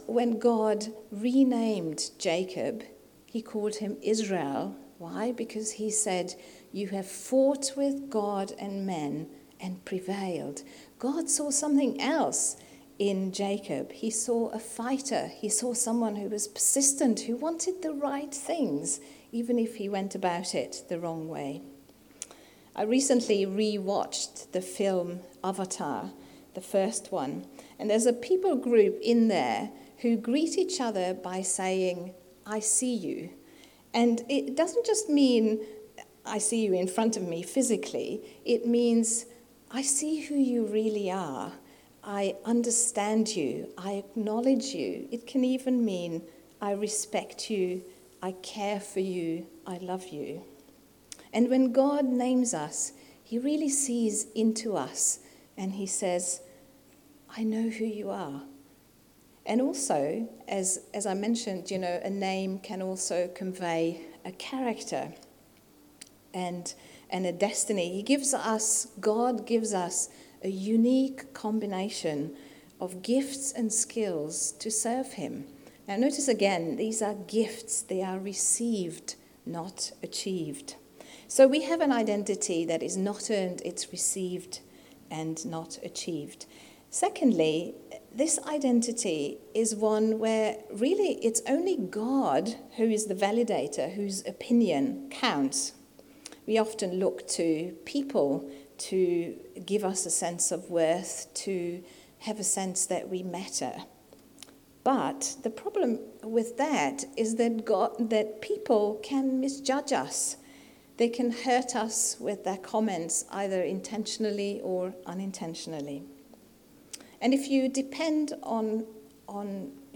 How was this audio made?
Unfortunately, the recording only begins part-way through the sermon.